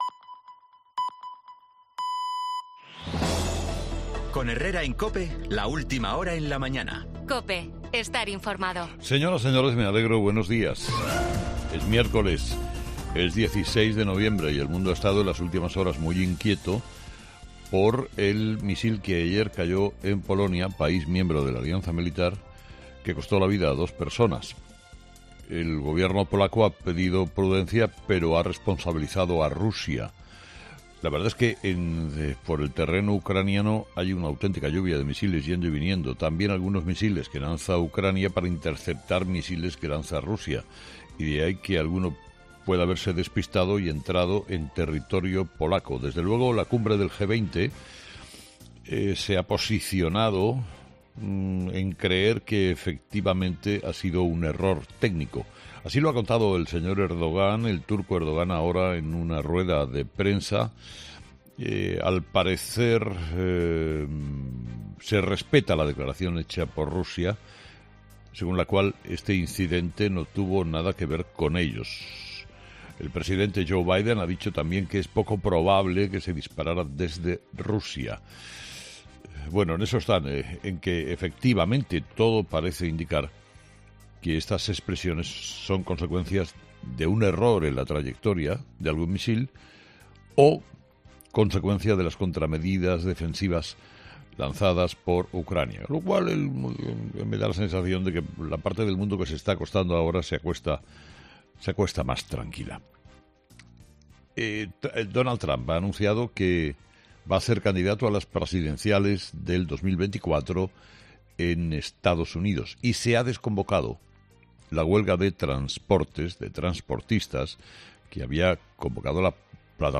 Herrera cuenta la última hora sobre los misiles caídos en territorio ruso